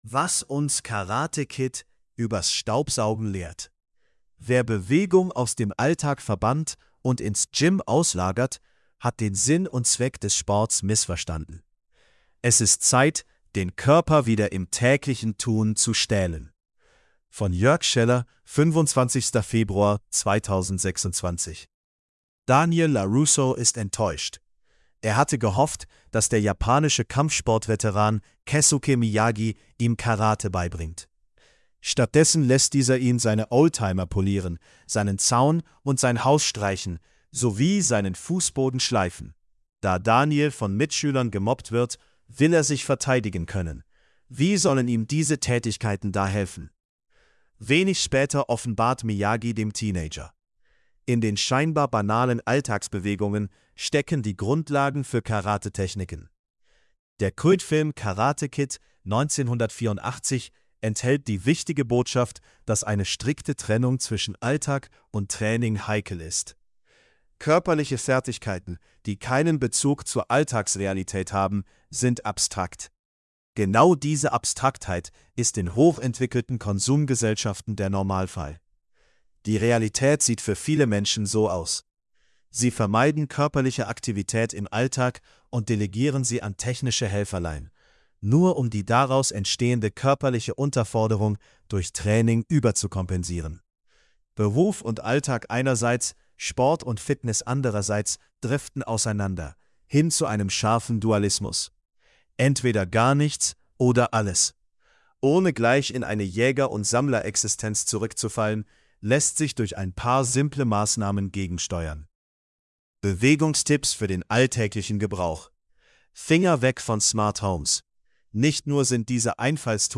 Was_uns_Karate_Kid_bers_Staubsaugen_lehrt_male.mp3